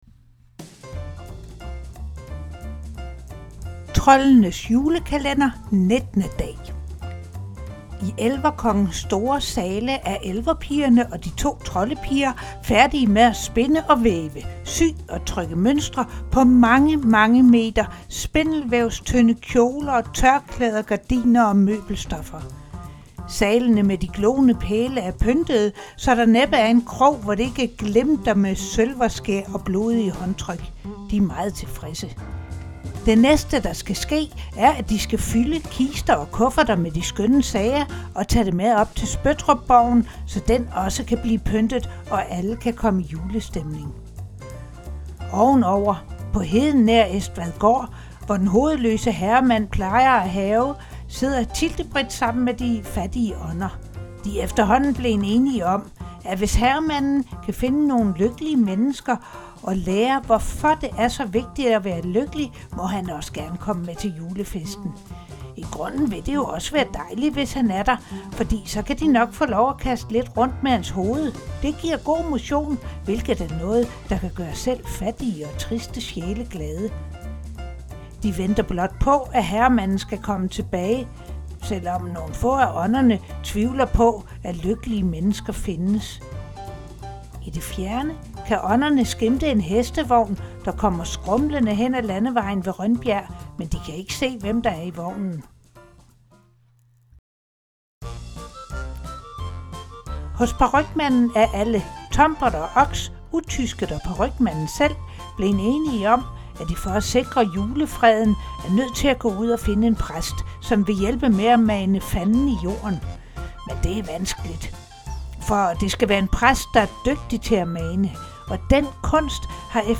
Sagn